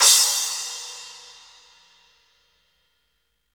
Index of /90_sSampleCDs/Roland L-CDX-01/CYM_FX Cymbals 1/CYM_Splash menu
CYM 13 SPL0B.wav